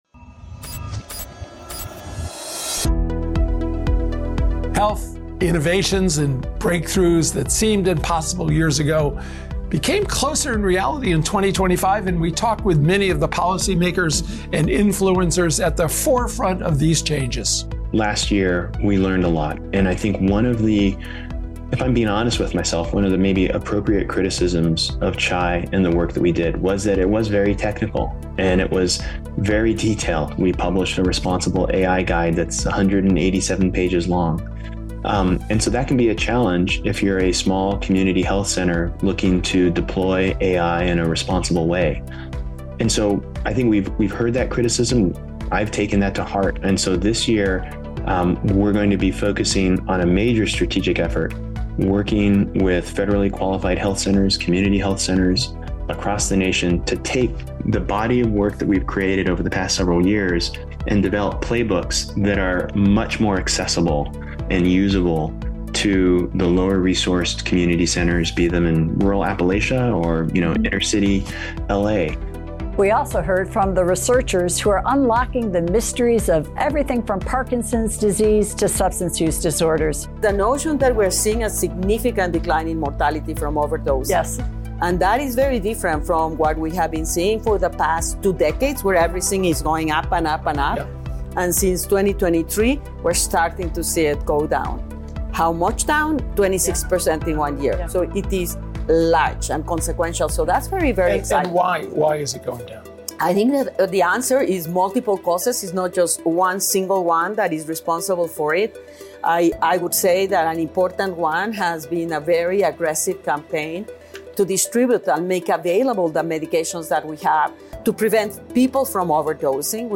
We revisit pivotal conversations with leaders driving real-world change, including: